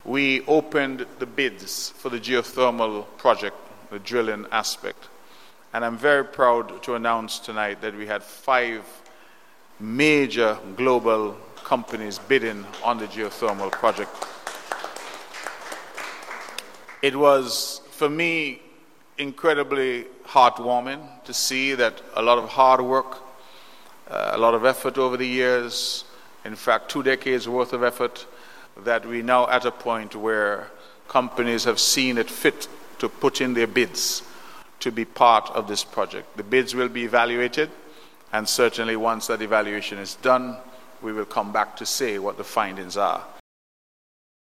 The first in a series of town hall meetings was held on Monday, June 30th 2025, at the Pond Hill Community Centre, in the St. John’s Constituency. In his opening remarks, Premier of Nevis, the Hon. Mark Brantley gave this significant update that occurred on Monday: